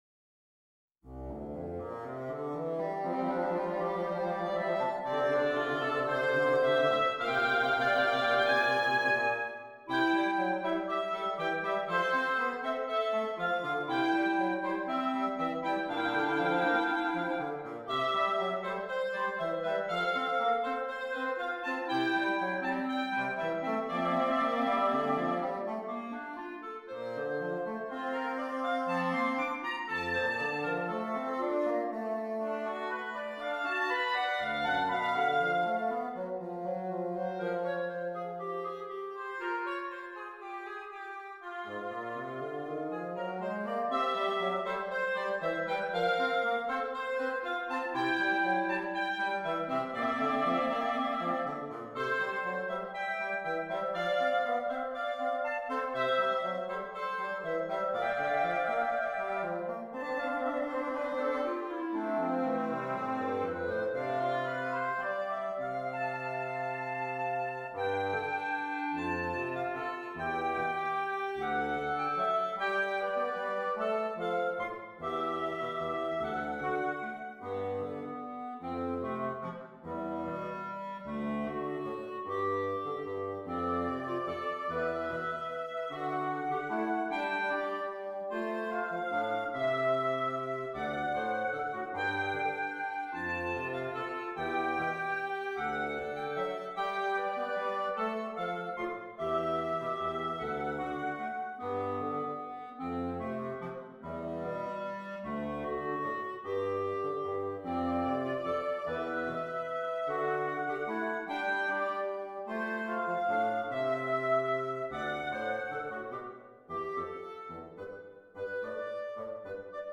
At The County Fair - Trio Oboe Clarinet Bassoon
This is a chamber music arrangement of an orchestra work I composed.